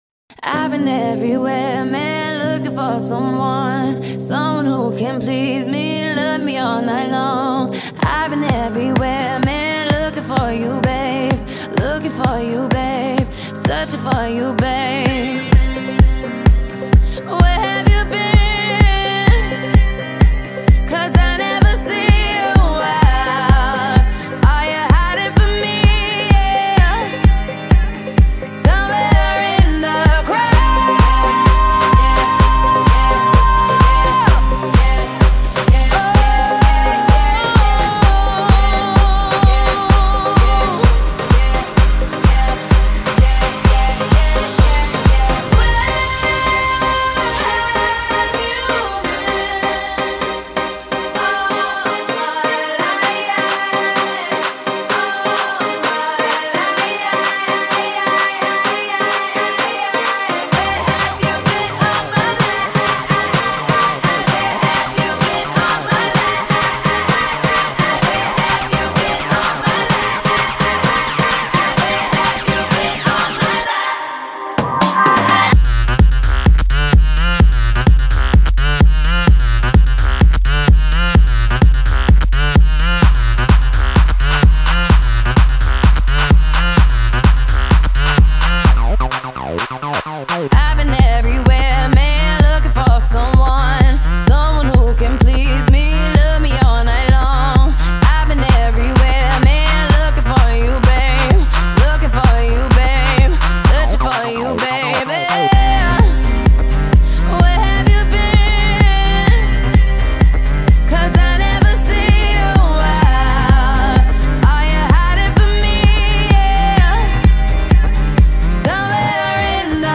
دنس پاپ و الکترونیک
صدای قدرتمند
ضرب‌آهنگ کوبنده